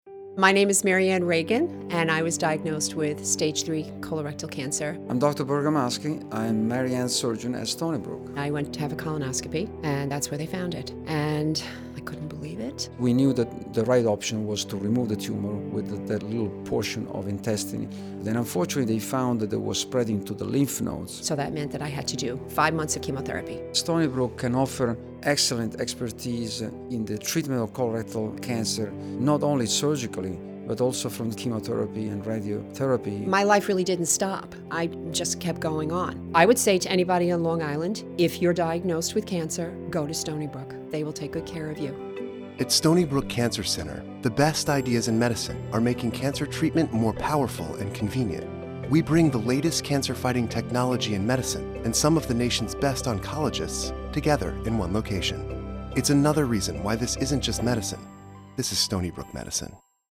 2013 Stony Brook Medicine Advertising Campaign - Radio Spots